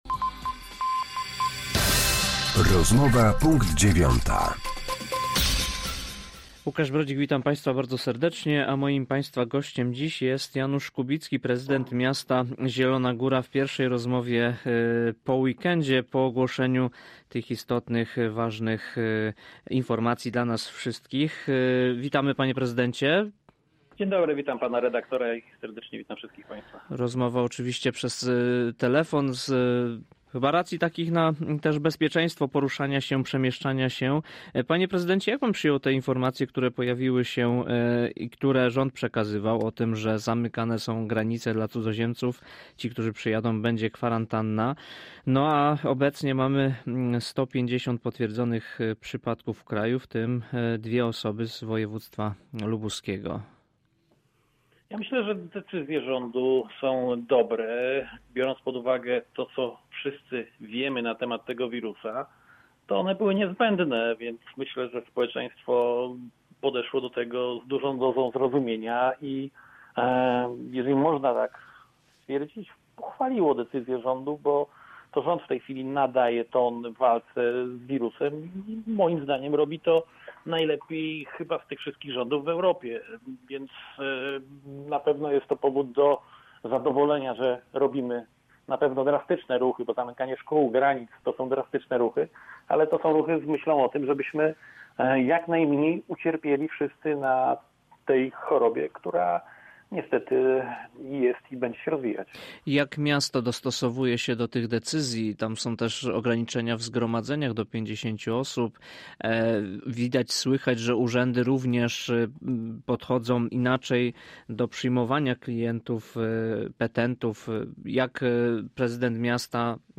Z prezydentem miasta Zielona Góra rozmawia